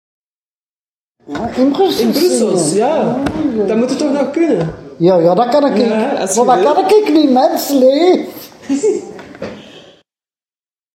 intergenerationele ontmoeting
brussels_zingen_sample.mp3